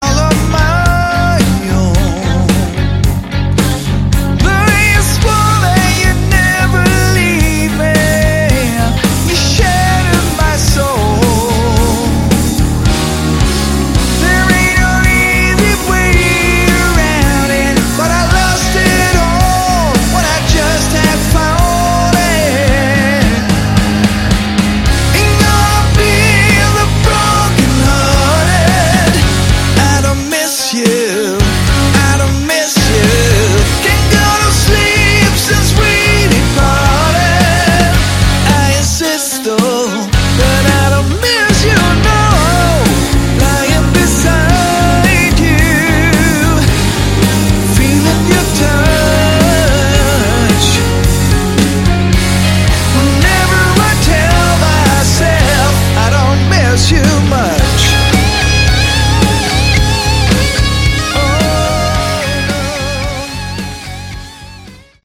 Category: Hard Rock
guitar, vocals, keyboards
bass
drums